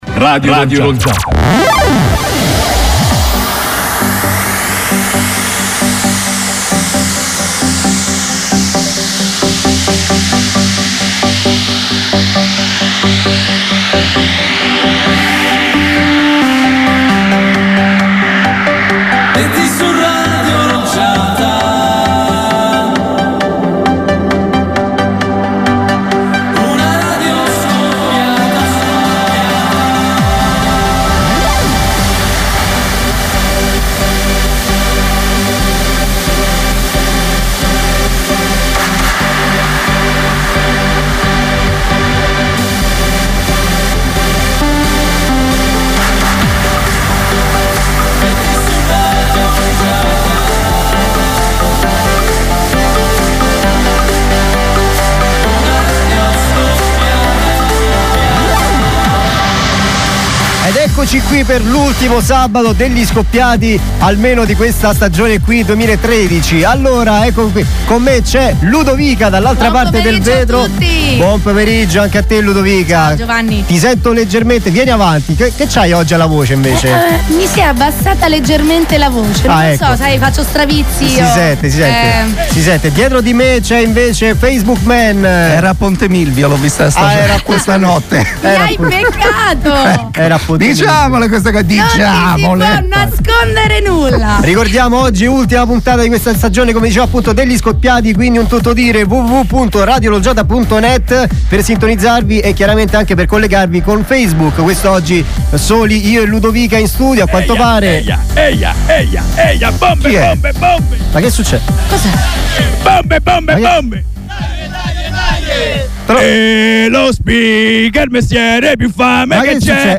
Podcast: Gli Scoppiati in diretta su Radio L’Olgiata per l’ultima puntata della prima stagione con tutti i partecipanti a raccolta.